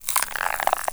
ALIEN_Insect_14_mono.wav